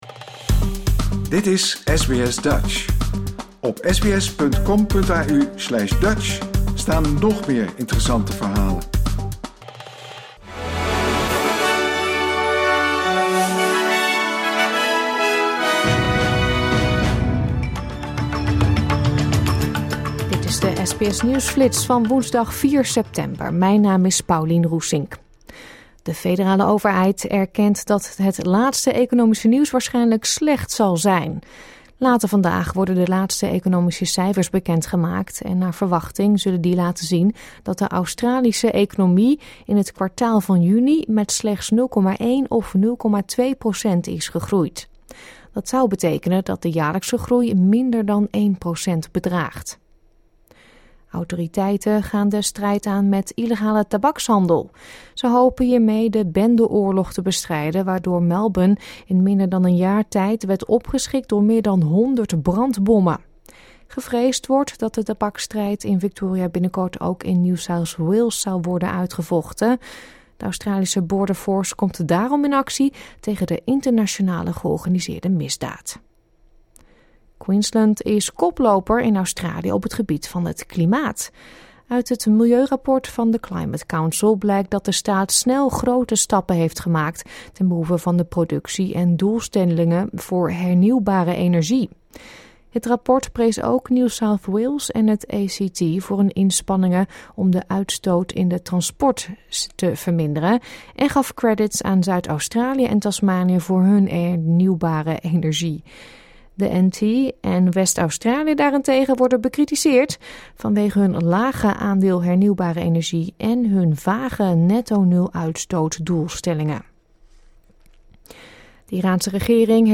Dit is de SBS Nieuwsflits van woensdagochtend 4 september.